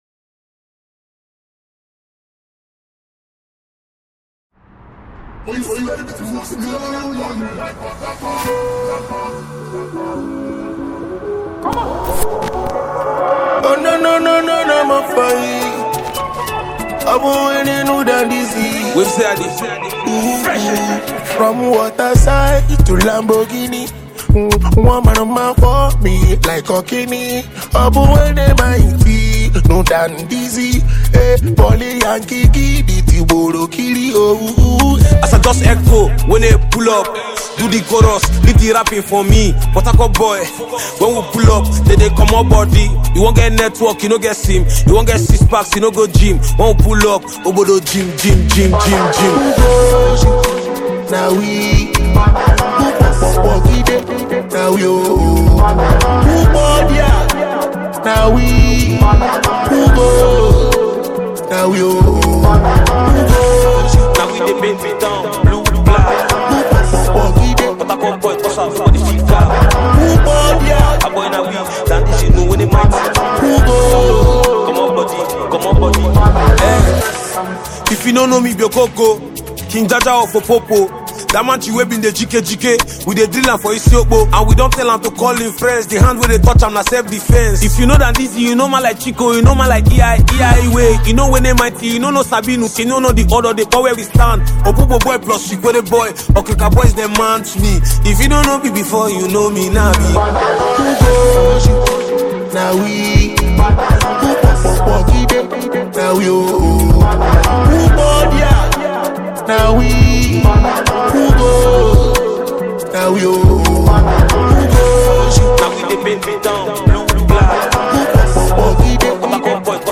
is an uptempo track
Genre: Afrobeat